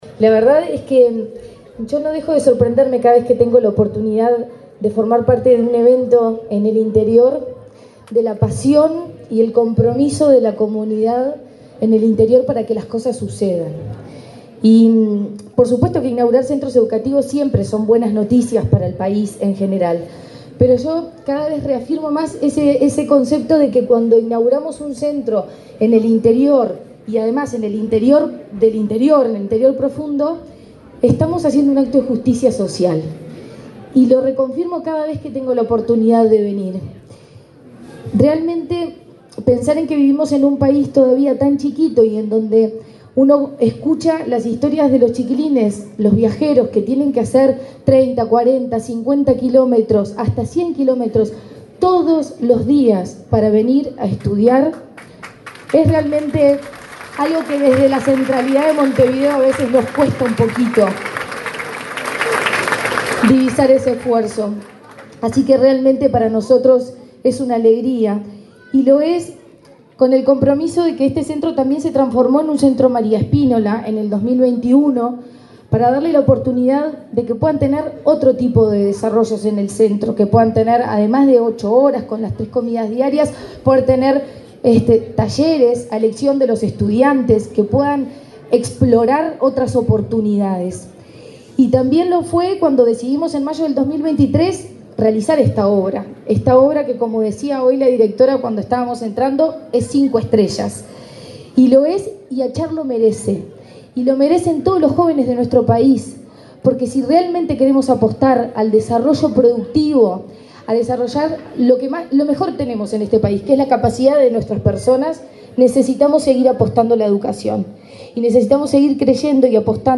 Palabras de la presidenta de la ANEP, Virginia Cáceres
Palabras de la presidenta de la ANEP, Virginia Cáceres 02/09/2024 Compartir Facebook X Copiar enlace WhatsApp LinkedIn La presidenta de la Administración Nacional de Educación Pública (ANEP), Virginia Cáceres, participó, este lunes 2, en la inauguración del edificio del liceo rural de Achar, en el departamento de Tacuarembó.